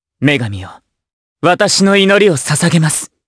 Lucias-Vox_Skill3_jp.wav